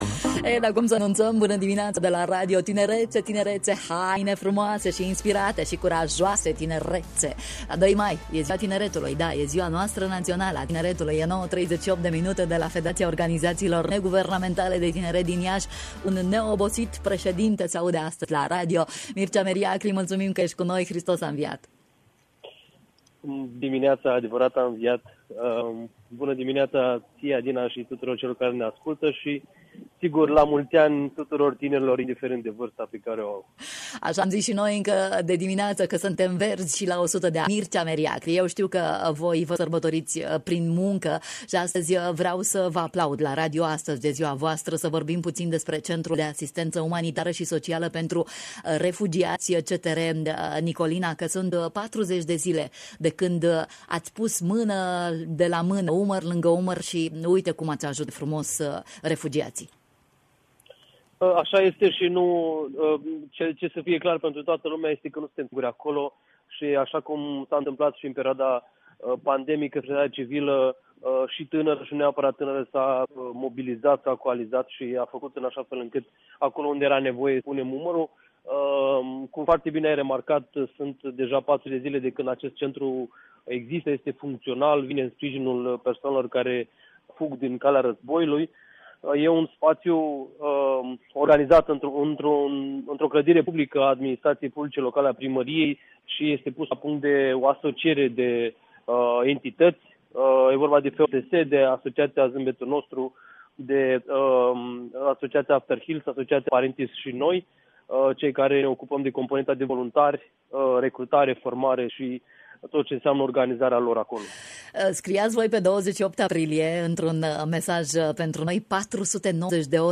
De Ziua Tineretului, 2 mai, Radio România Iaşi ajunge la Centrul de Asistentă Umanitară si Socială pentru Refugiaţi CTR Nicolina, coordonat de Federaţia Organizaţiilor Neguvernamentale de Tineret Iaşi.